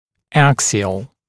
[‘æksɪəl][‘эксиэл]аксиальный, осевой